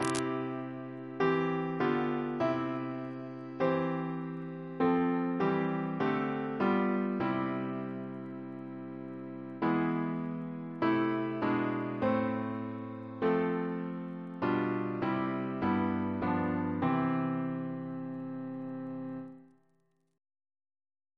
Double chant in C Composer: Walter Parratt (1841-1924) Reference psalters: ACB: 4; CWP: 98; H1940: 682; RSCM: 107